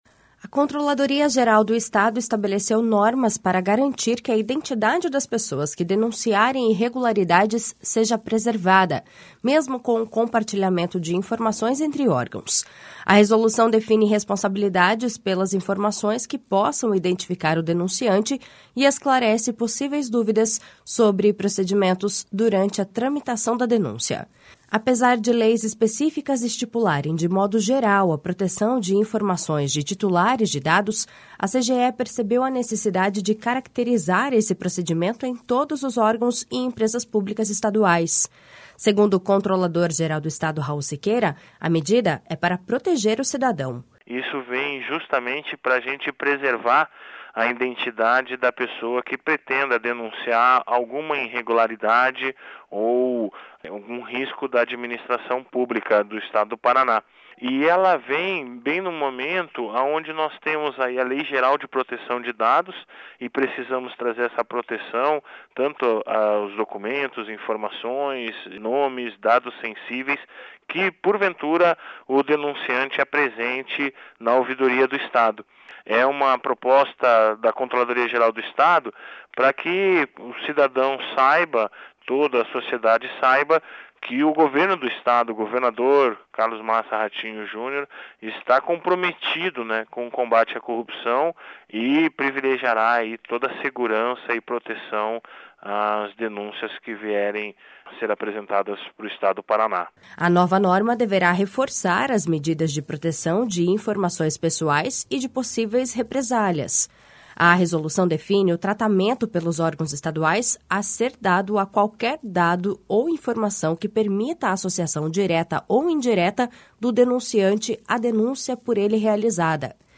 Segundo o controlador-geral do Estado, Raul Siqueira, a medida é para proteger o cidadão.// SONORA RAUL SIQUEIRA.// A nova norma deverá reforçar as medidas de proteção de informações pessoais e de possíveis represálias.